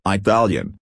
(ɪˈtælyən)   italiano (-a)